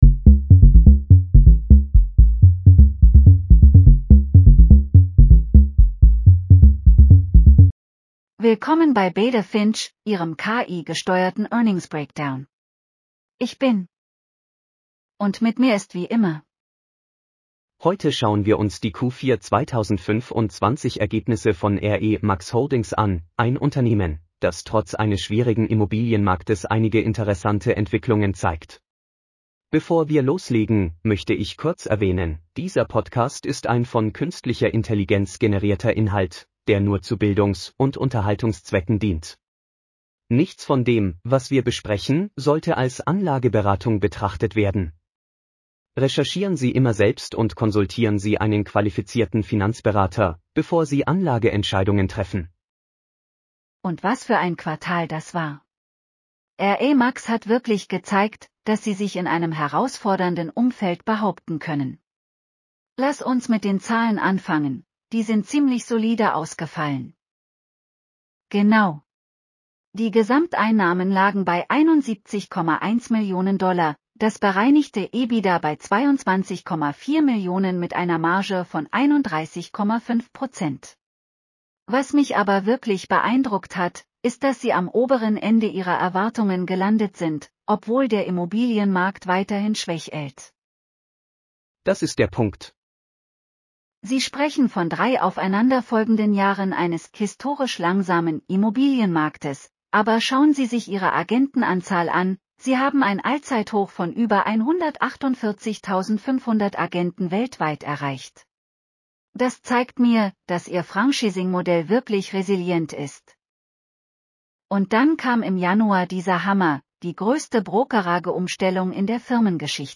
• AI-generated insights and analysis